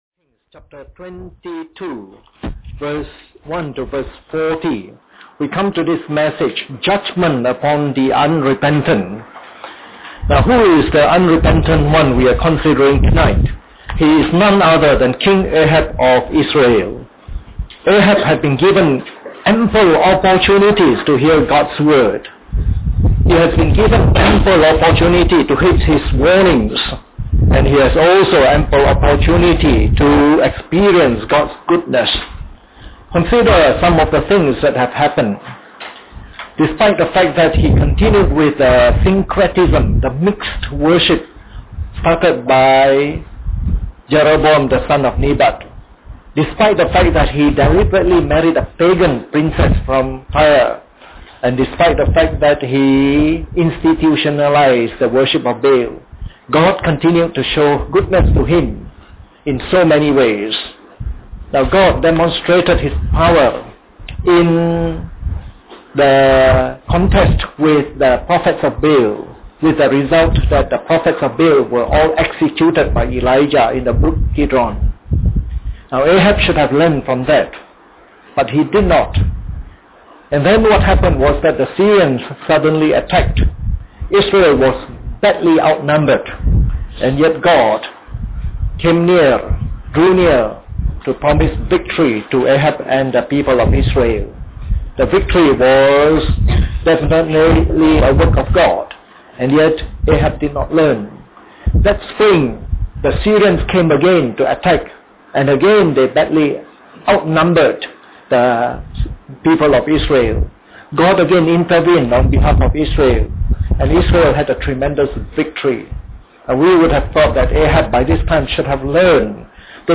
Part of the “1 Kings” message series delivered during the Bible Study sessions.